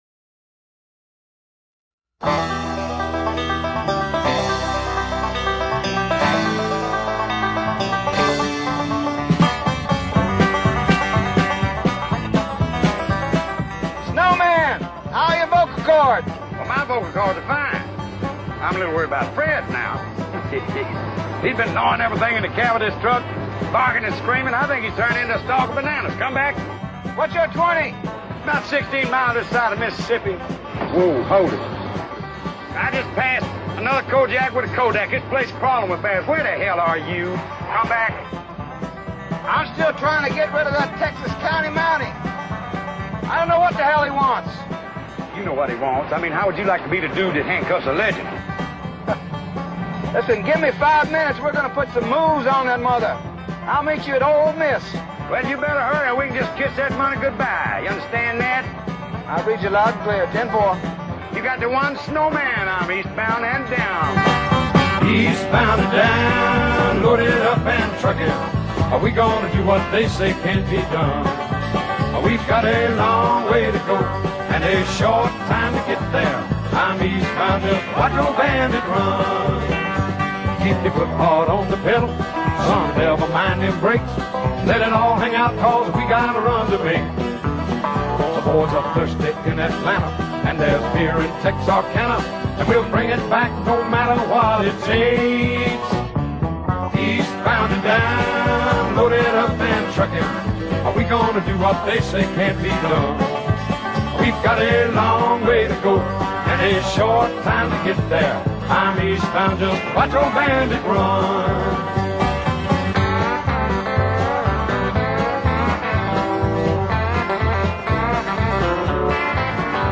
with incidental movie dialogue